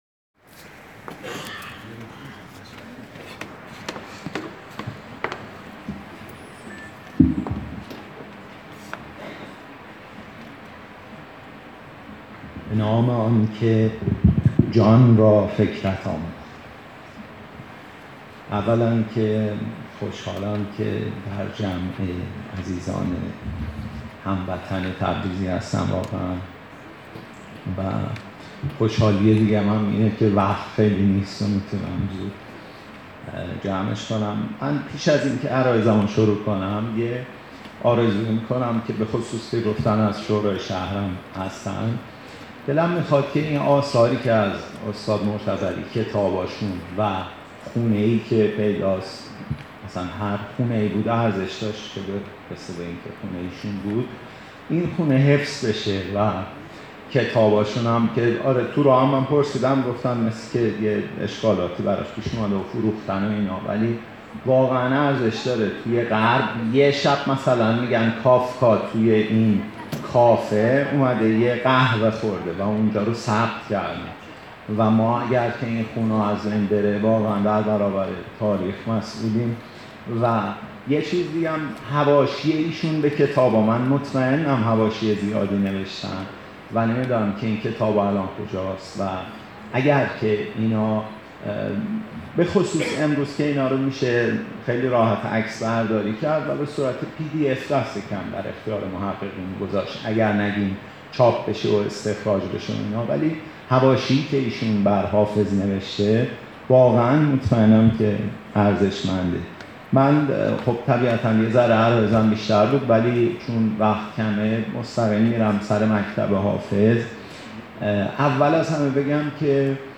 چهارشنبه 9 امرداد 1398؛ تبریز: مجتمع فرهنگی 29 بهمن